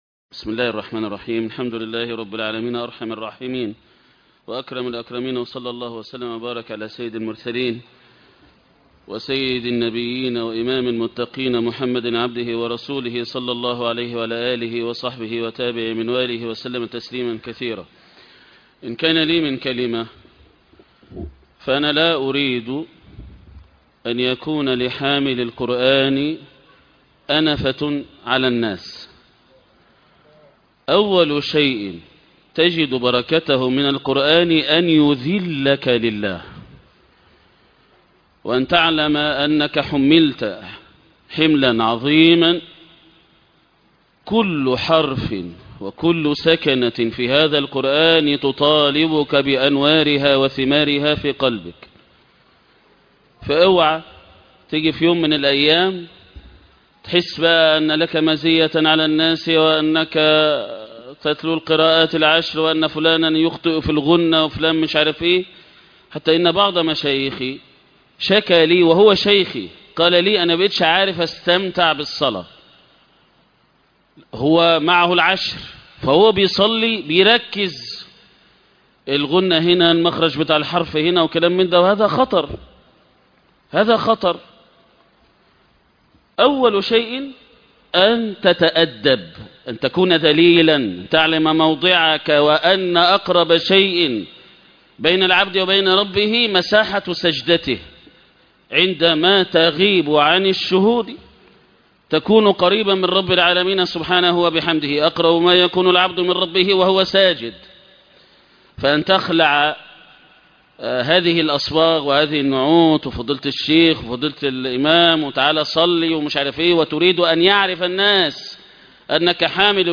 محاضرة شرفك في الذل لربك